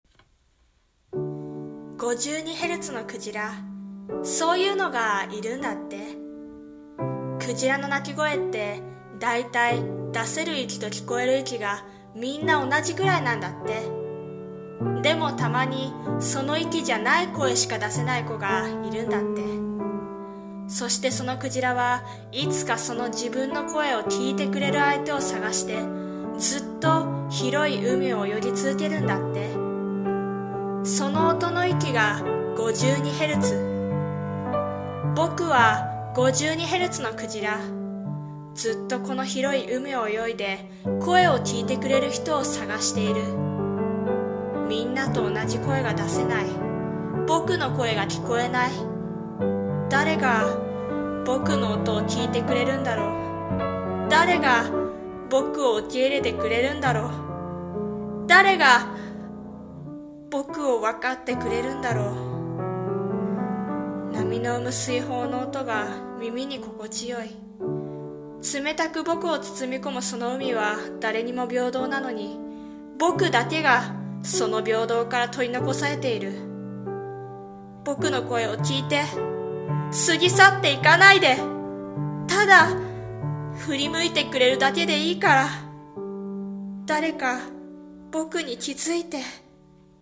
さんの投稿した曲一覧 を表示 【1人用】52ヘルツの声【朗読】